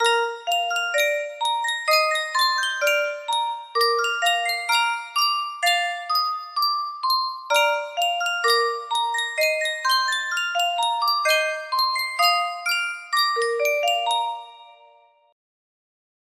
Sankyo Music Box - 花 滝廉太郎 CDA music box melody
Full range 60